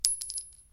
sfx_cassing_drop_0.mp3